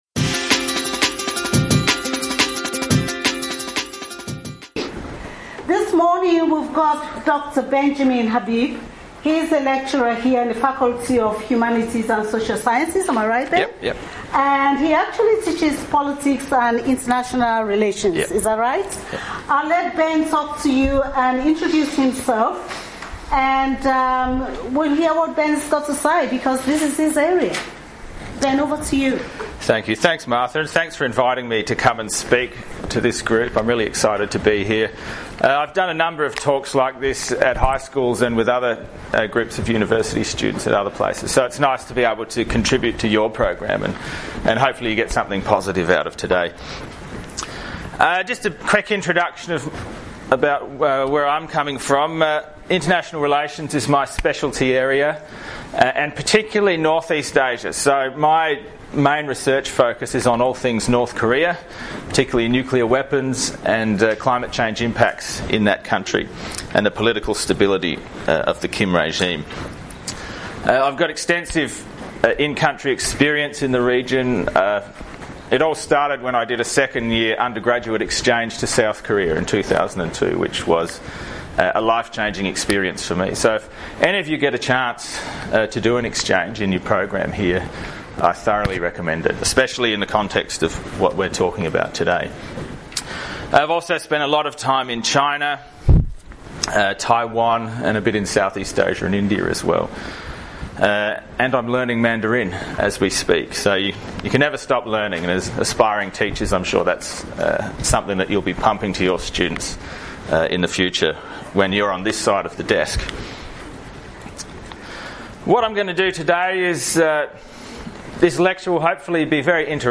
On Thursday 19th September 2013 I had the pleasure of presenting a guest lecture to education students at La Trobe University, studying the 2nd year subject Cultural and Indigenous Issues (EDU2CII).